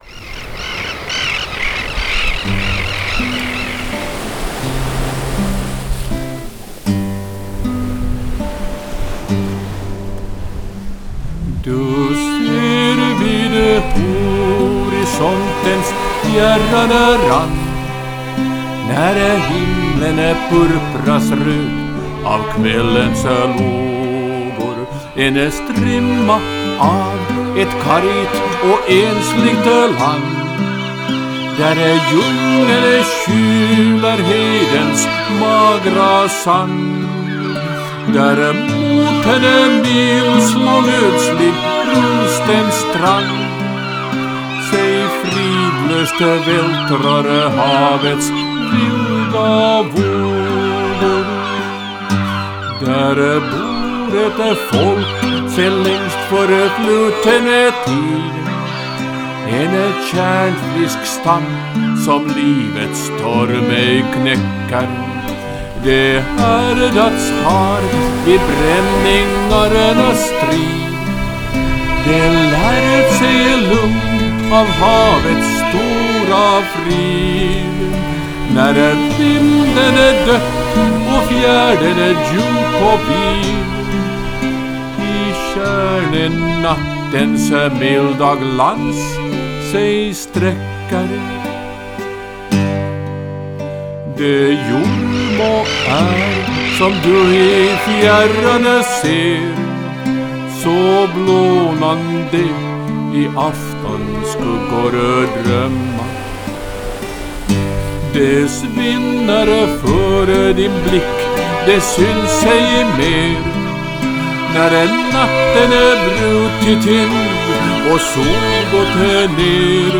Fioljustering, kinesisk Guarnierikopia
Nedan ges ett eget exempel på samma fiol.
Jag spelade in melodin som separata track d.v.s. alla stämmor är mina egna.